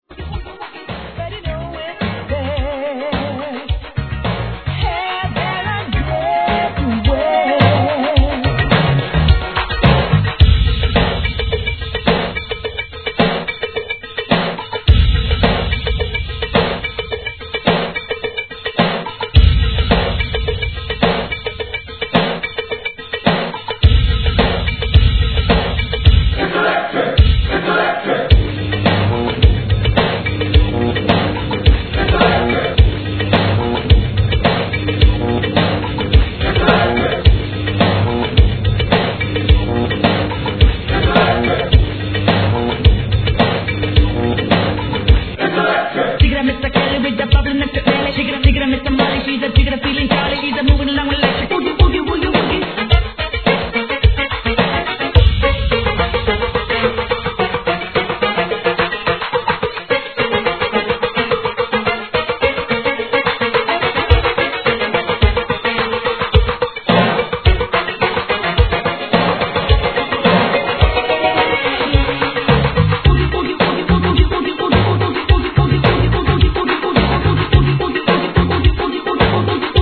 (107 BPM)